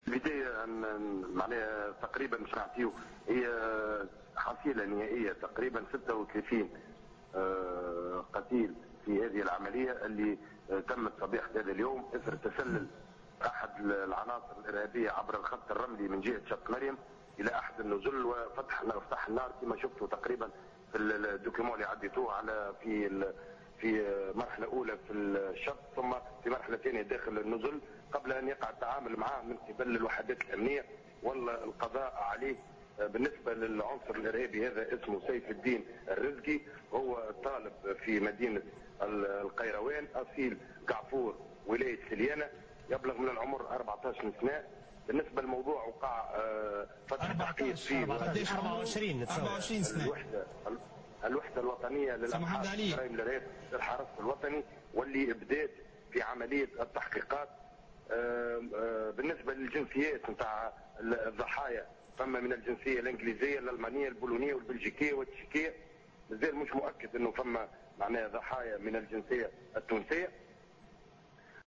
في مداخلة له على قناة الحوار التونسي